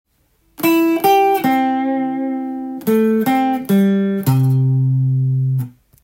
エレキギターで作りだす【羊文学のリズムをフレーズに変える方法】
全てAmペンタトニックスケールを使用しています。
ペンタトニックスケールで音程差は出すとメロディアスな
フレーズになりやすいですね。